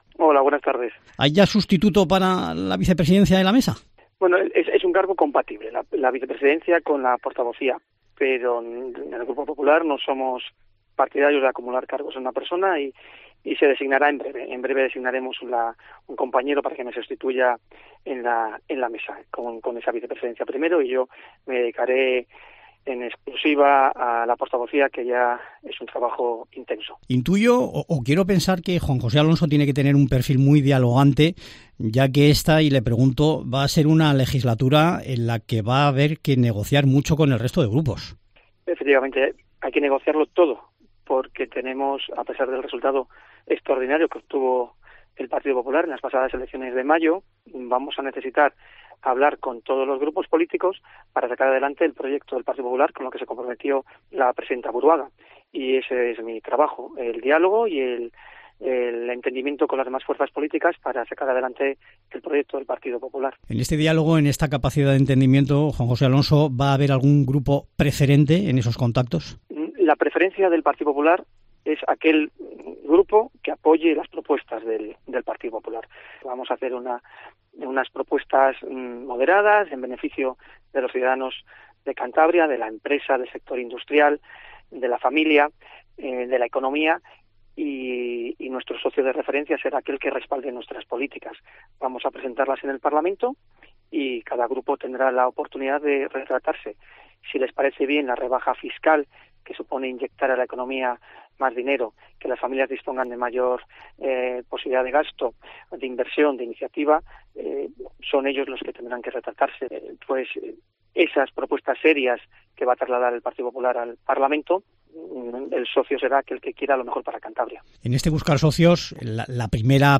Juan José Alonso, portavoz del grupo popular en el Parlamento de Cantabria
La Ley de Presupuestos para 2024 es la iniciativa "prioritaria" para el PP en el inicio de esta legislatura en el Parlamento de Cantabria, unas cuentas que comenzarán a negociar "sin socios de referencia", según ha adelantado en Mediodía COPE Cantabria, el nuevo portavoz del grupo Juan José Alonso.